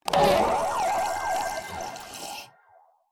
A_Boxy_Lunchboxy_LongJump.wav